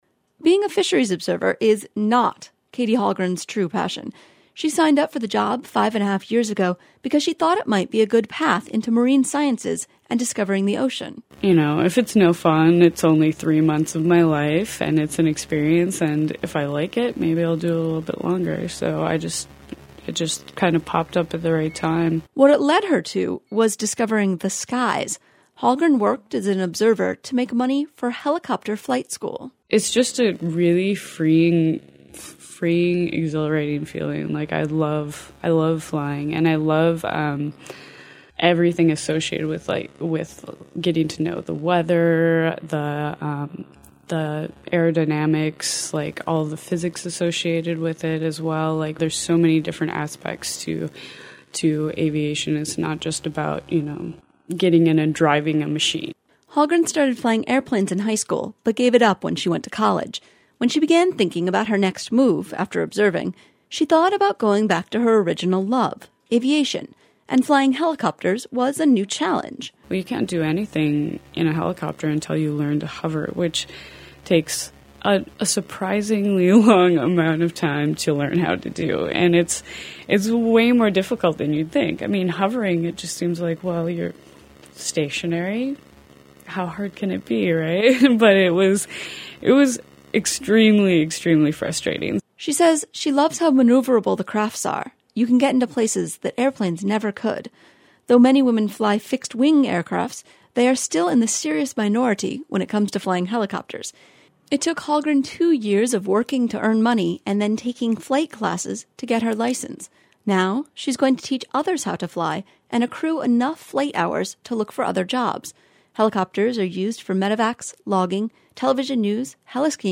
Unalaska, AK – In honor of International Women's Day, KUCB and USAFV are interviewing women from around the world whose lives have brought them to Unalaska.